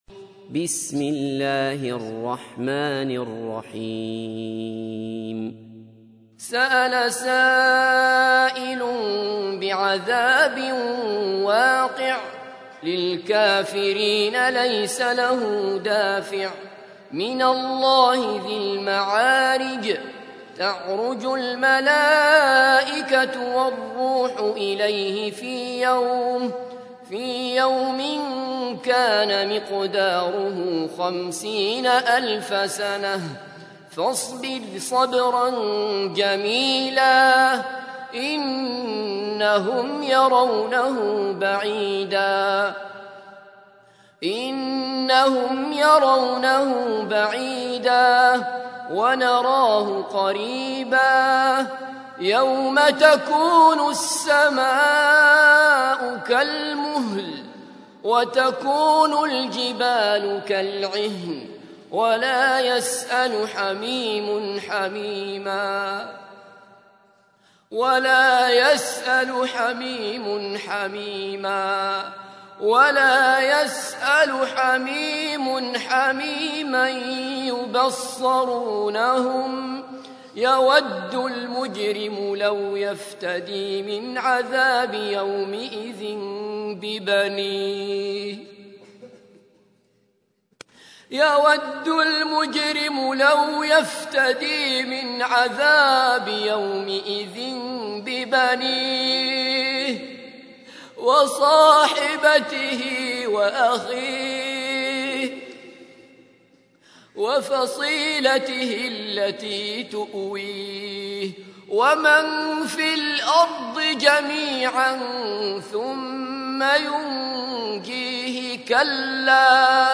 تحميل : 70. سورة المعارج / القارئ عبد الله بصفر / القرآن الكريم / موقع يا حسين